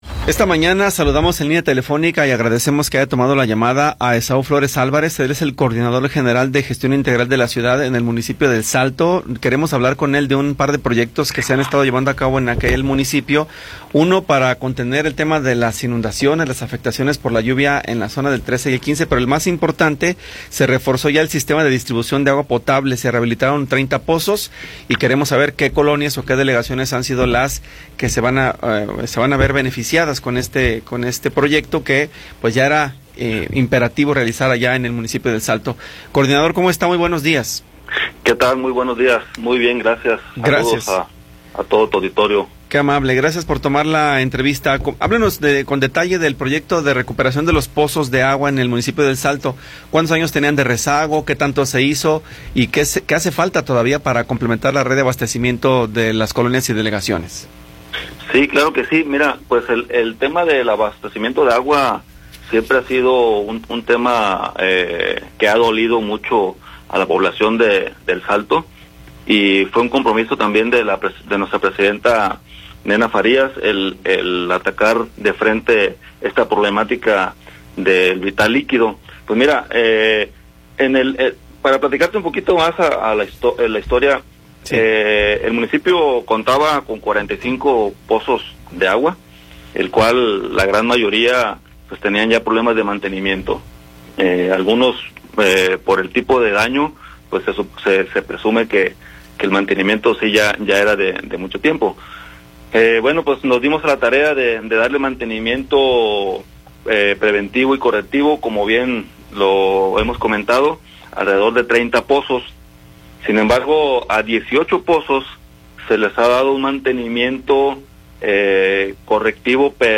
Entrevista con Esaú Flores Álvarez